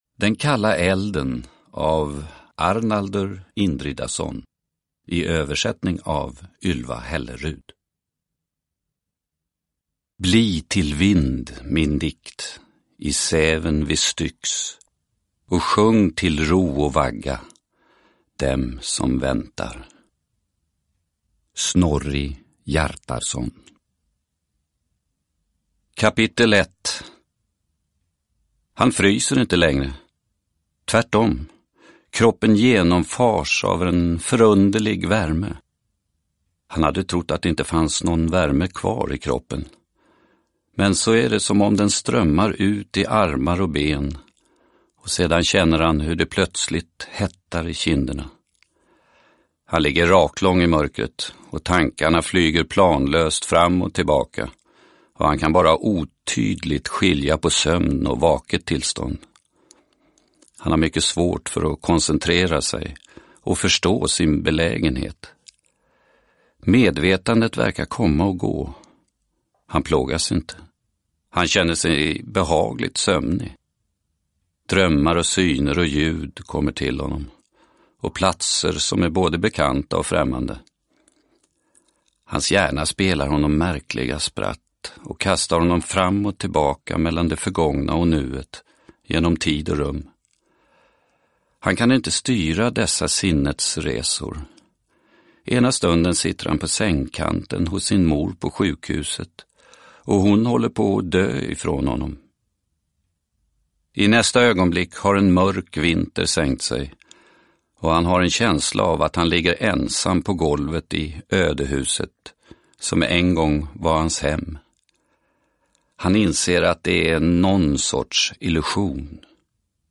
Den kalla elden – Ljudbok – Laddas ner